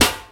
• 2000s Sharp Snare Sound D Key 84.wav
Royality free steel snare drum sound tuned to the D note. Loudest frequency: 3696Hz